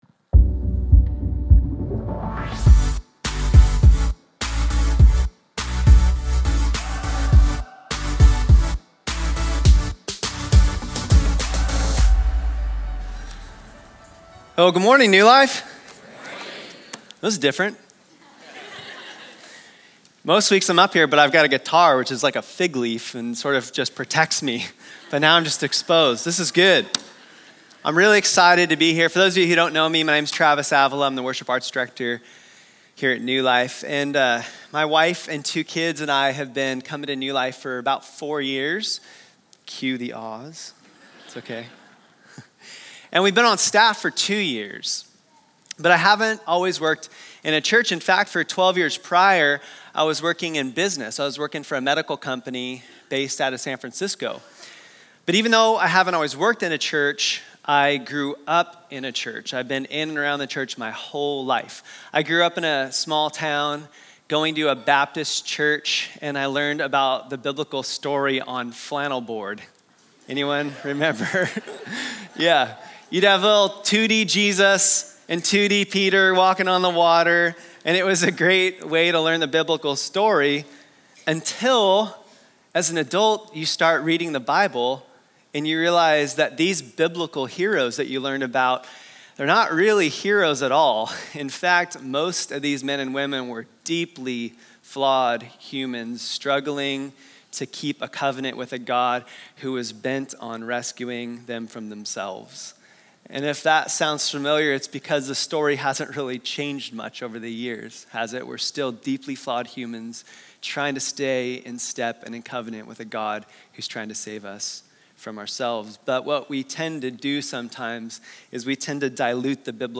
A message from the series "Stand Alone Sermons."